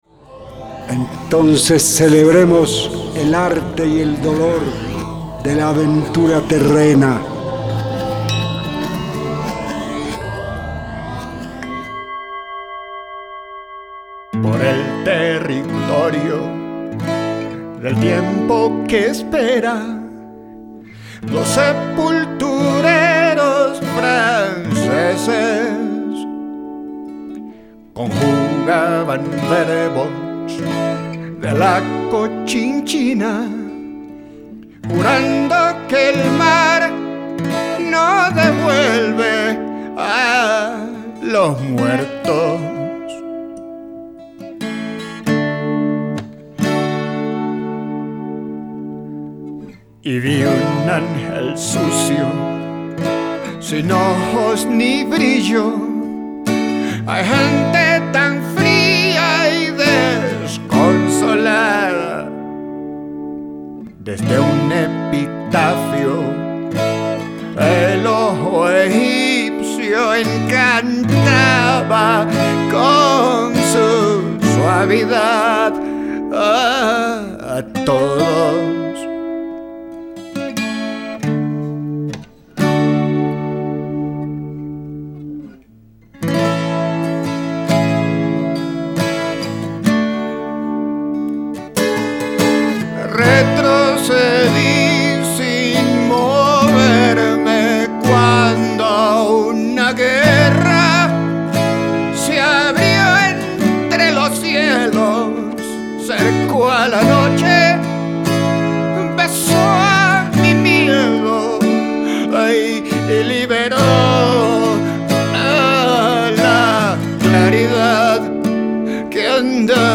guitarra electroacústica, voz.